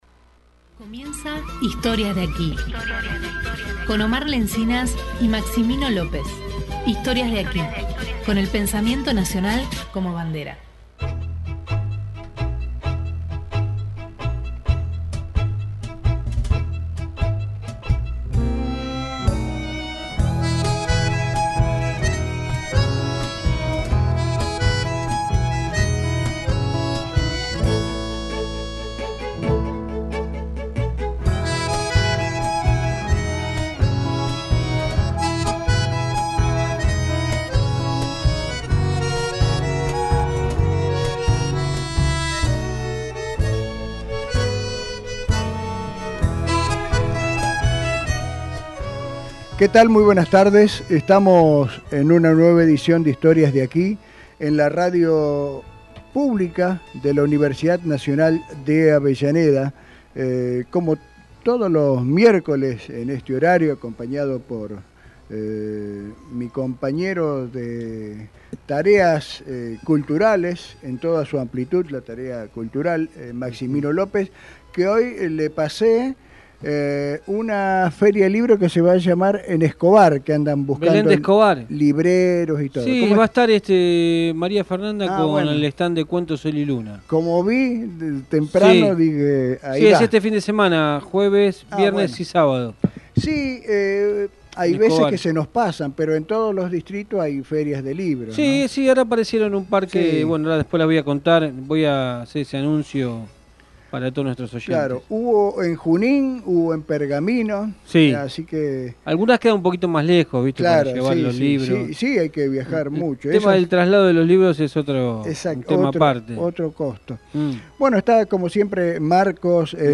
Historias de aquí Texto de la nota: Historias de aquí Con el pensamiento nacional como bandera. Música regional, literatura y las historias que están presentes en la radio.